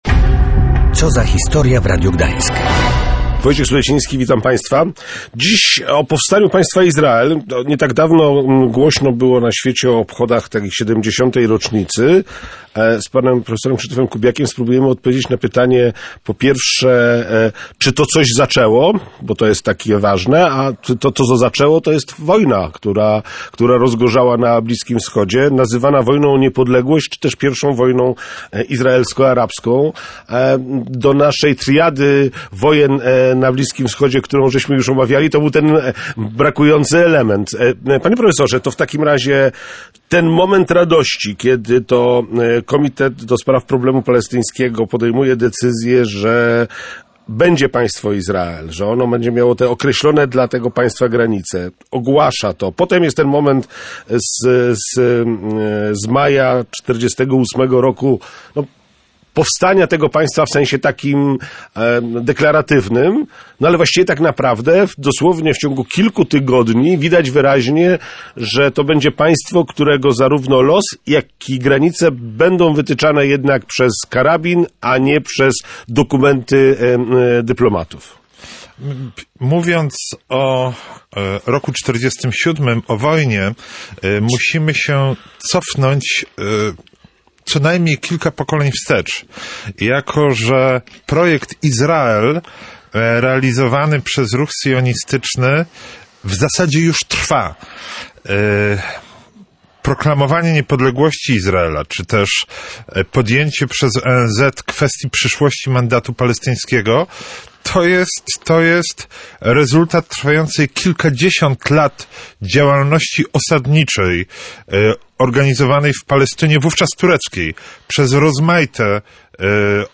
– Podjęcie przez NFZ kwestii przyszłości mandatu palestyńskiego to rezultat trwającej kilkadziesiąt lat działalności osadniczej, organizowanej wówczas w arabskiej Palestynie – mówił na antenie Radia Gdańsk polski historyk wojskowości. Zarówno przeszłość Izraela, jak i jego teraźniejszość, mija pod znakiem licznych konfliktów zbrojnych.